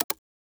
Click (12).wav